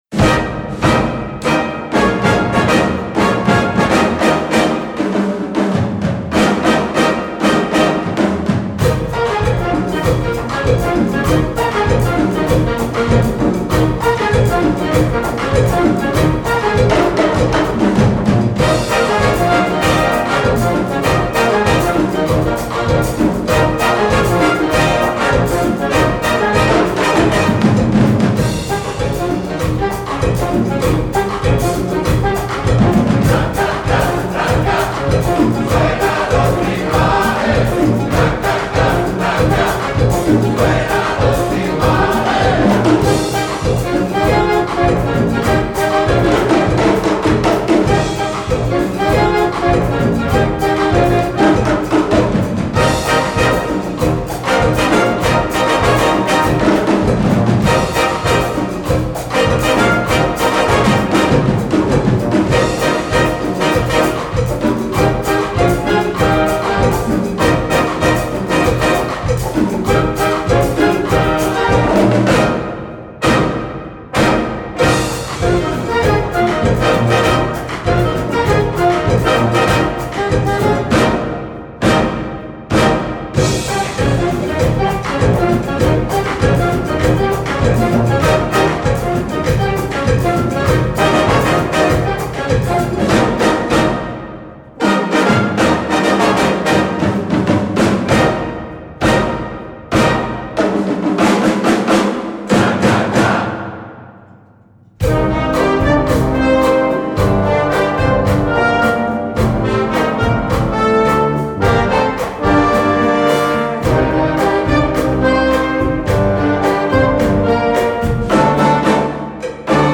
Partitions pour orchestre d'harmonie, ou - fanfare.
• View File Orchestre d'Harmonie